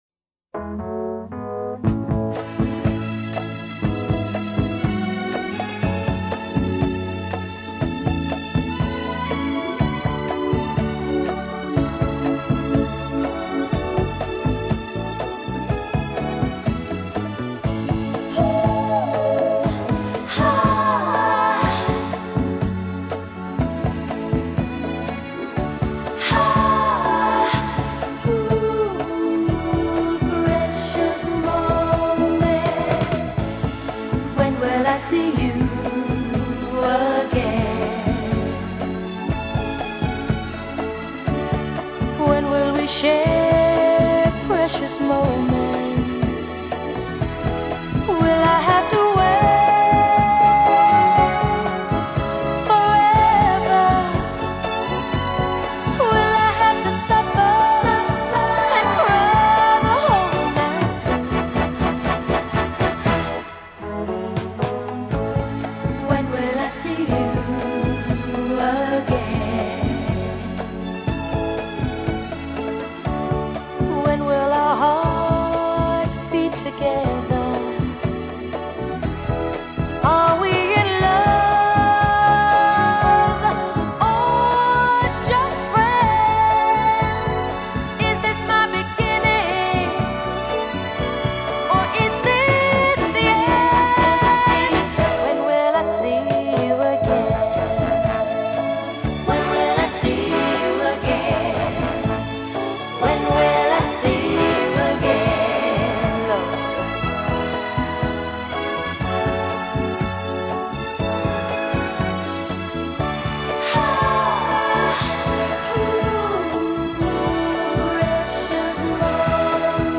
裡面收錄都是經典好聽的情歌